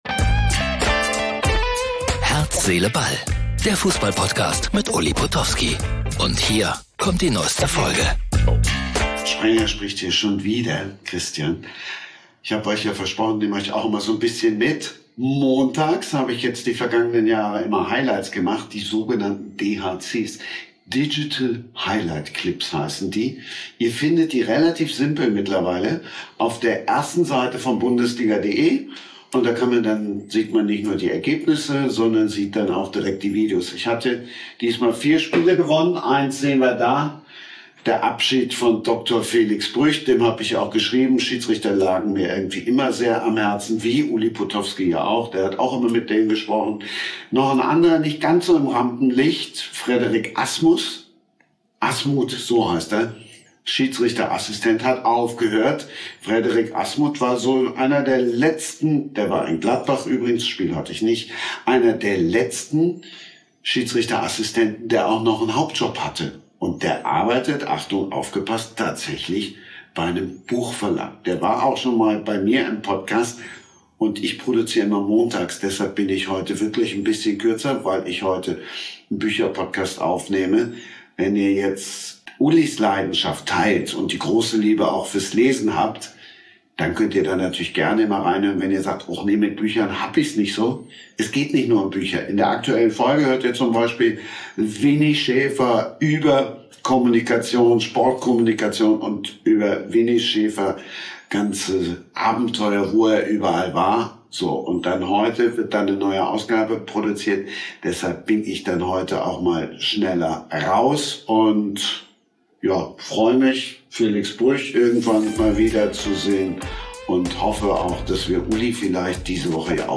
live aus dem Bundesliga- Studio in Köln